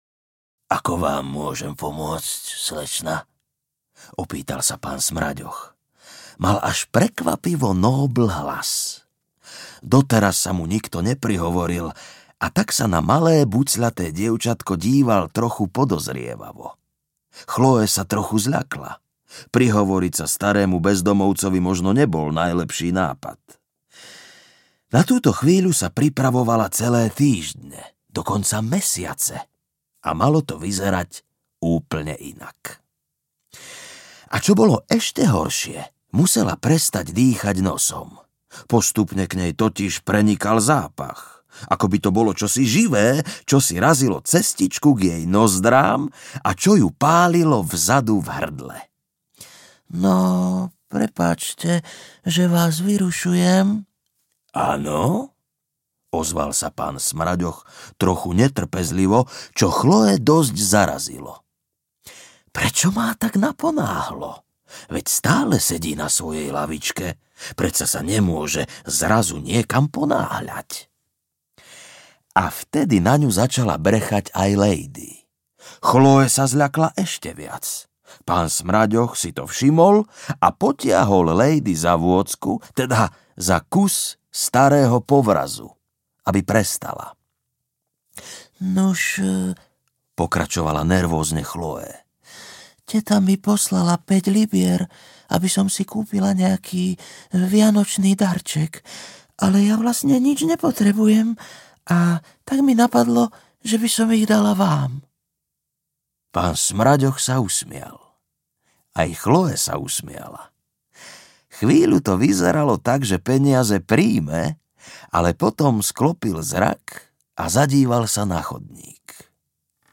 Pán Smraďoch audiokniha
Ukázka z knihy